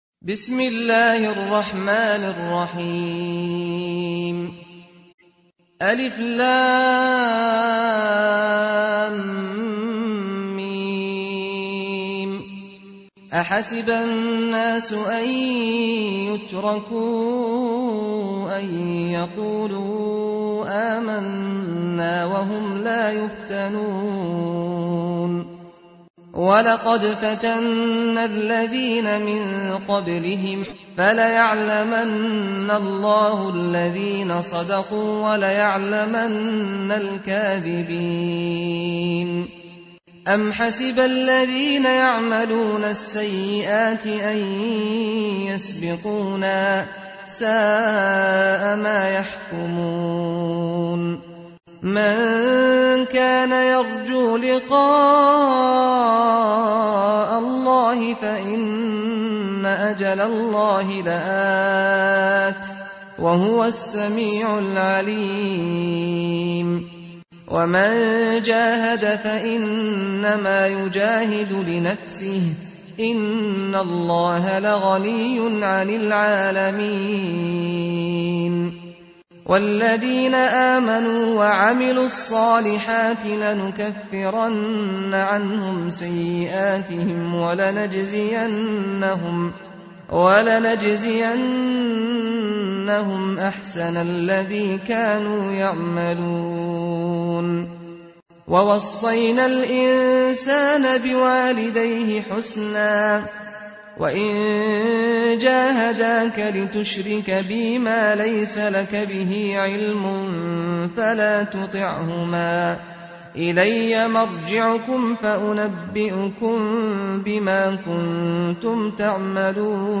تحميل : 29. سورة العنكبوت / القارئ سعد الغامدي / القرآن الكريم / موقع يا حسين